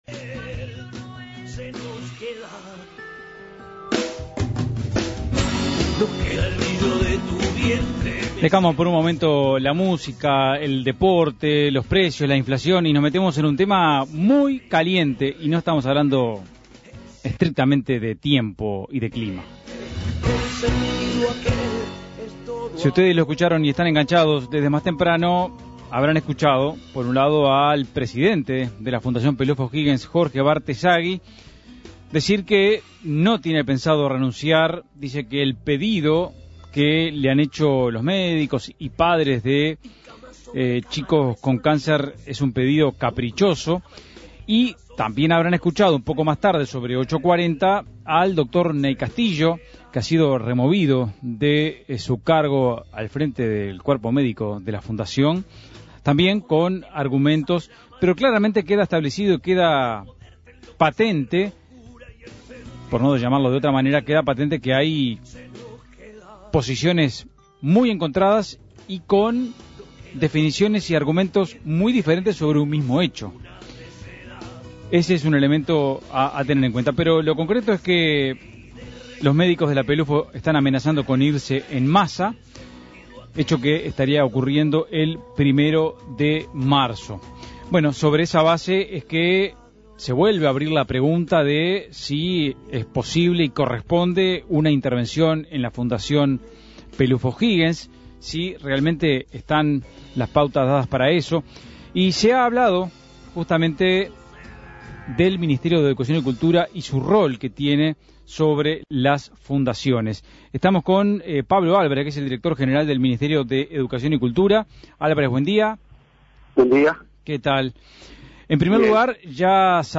Entrevista a Pablo Álvarez, director general del Ministerio de Educación y Cultura (MEC).